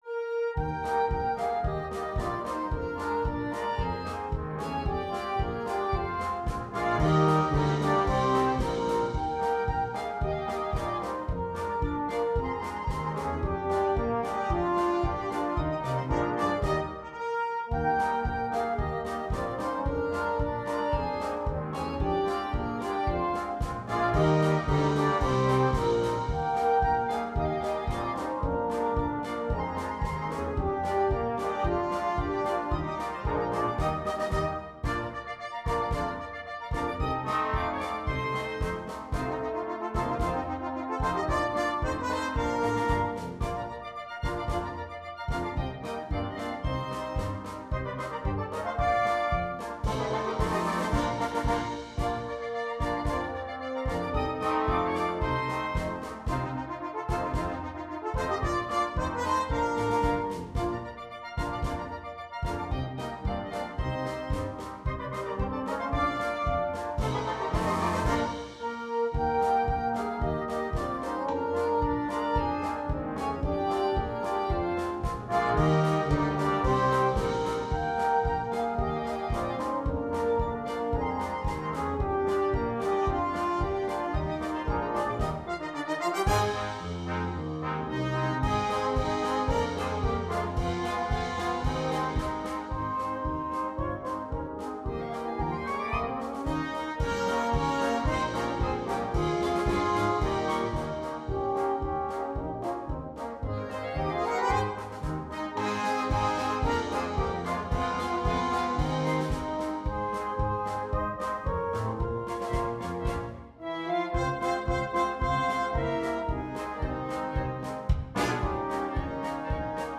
2. Egerlander-style band
Full Band
without solo instrument
Entertainment